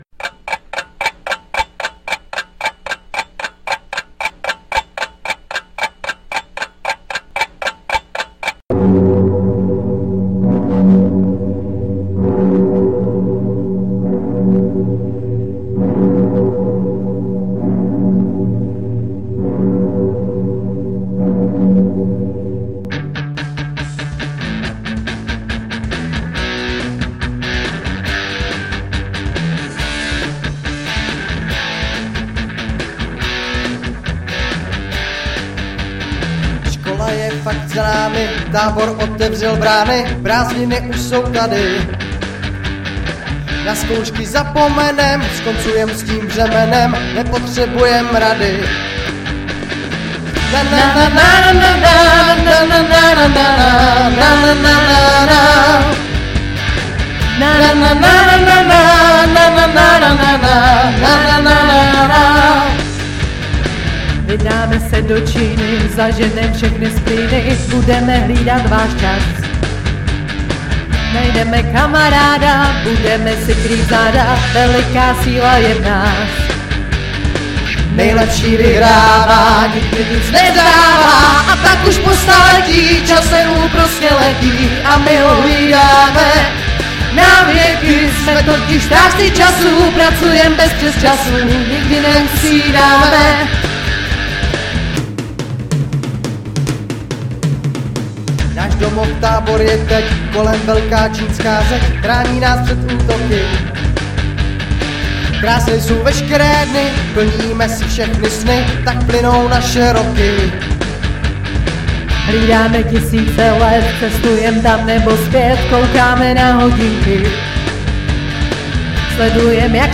coververze